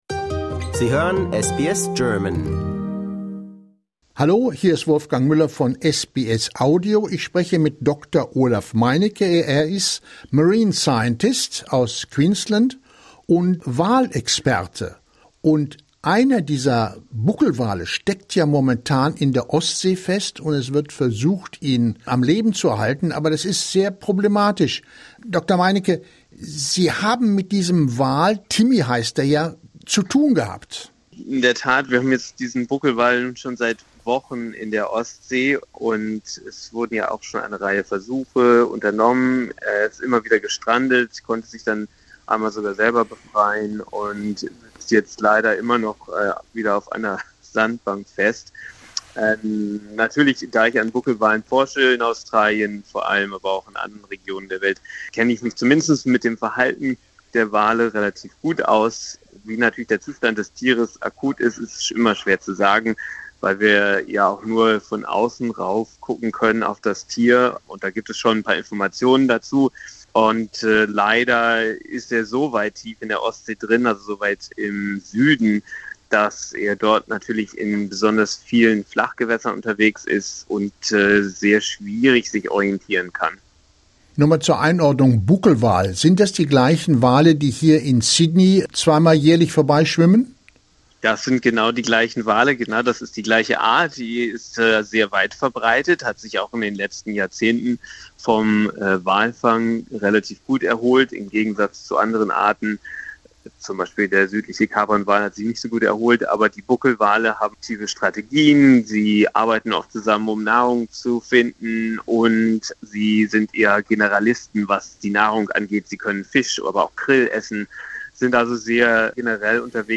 Discover more stories, interviews, and news from SBS German in our podcast collection.